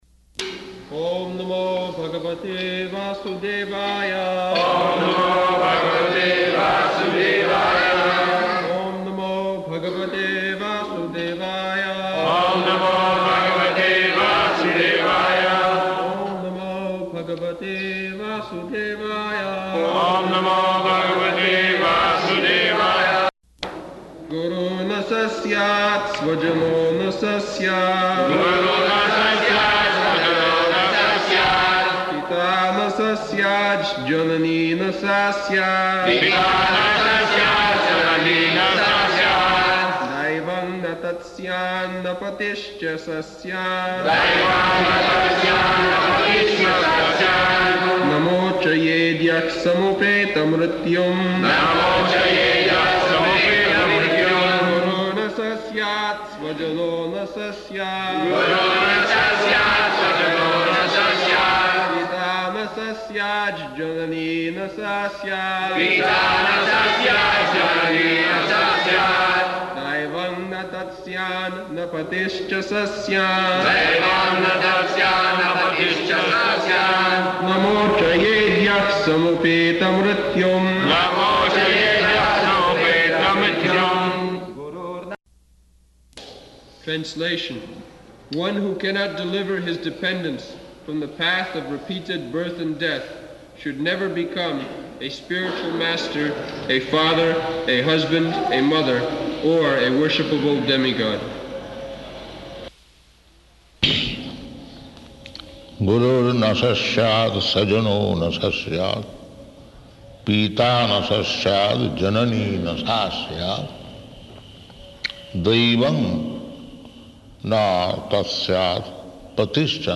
-- Type: Srimad-Bhagavatam Dated: November 6th 1976 Location: Vṛndāvana Audio file
[devotees repeat] [leads chanting of verse, etc.]